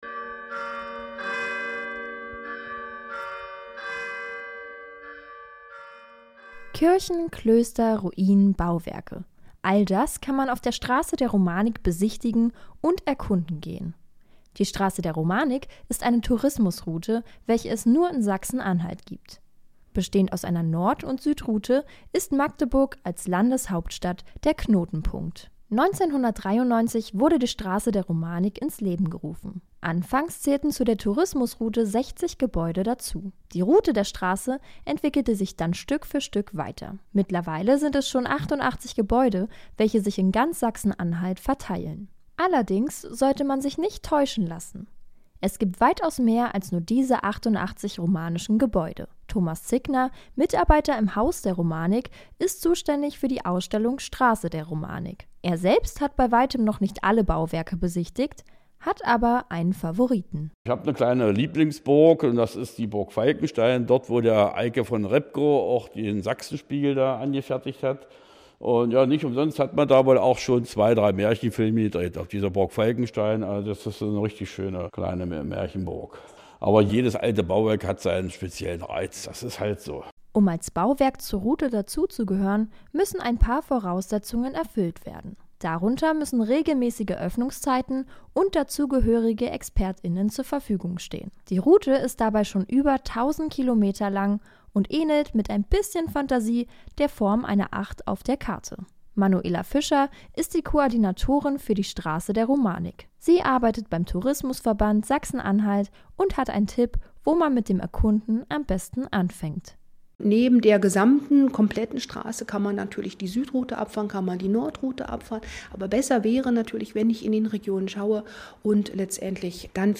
Beitrag